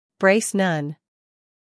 英音/ breɪs / 美音/ breɪs /